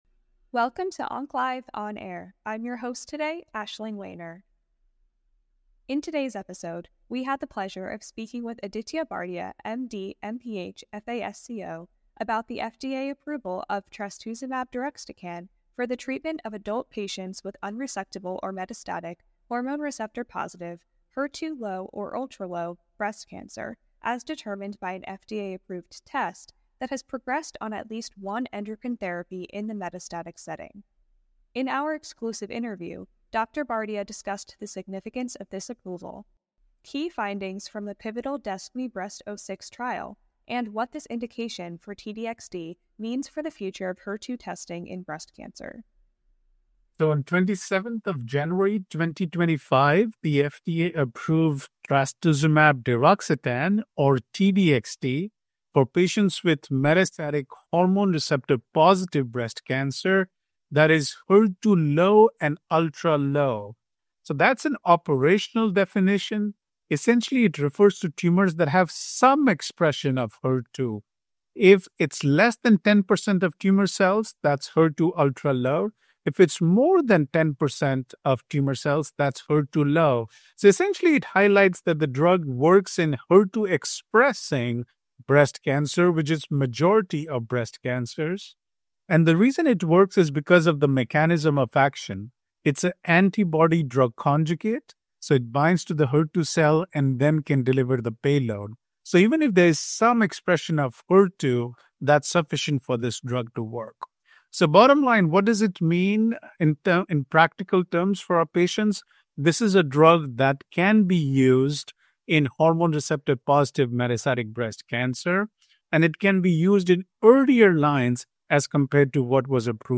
Fitness Audioboom OncLive® On Air Oncology Science Medicine Exclusive Interviews